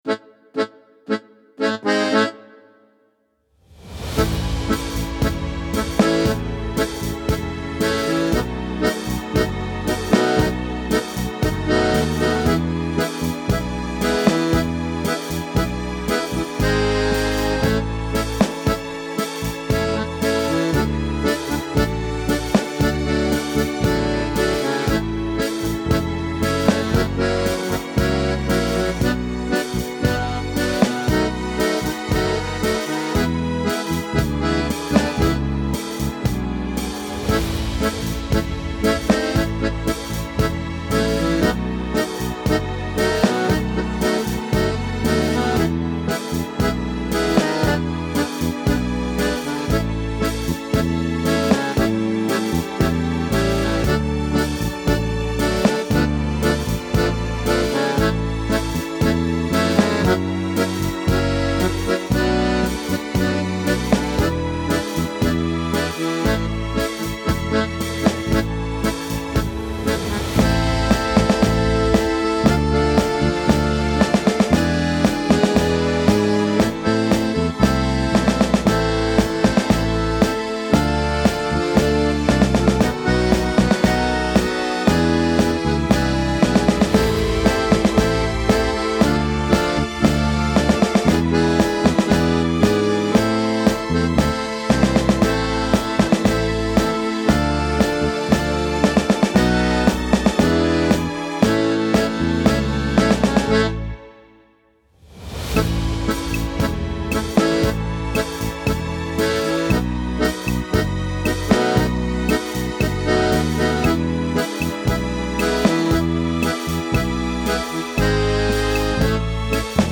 Übungsaufnahmen - Der alte Seemann
Runterladen (Mit rechter Maustaste anklicken, Menübefehl auswählen)   Der alte Seemann (Playback)
Der_alte_Seemann__5_Playback.mp3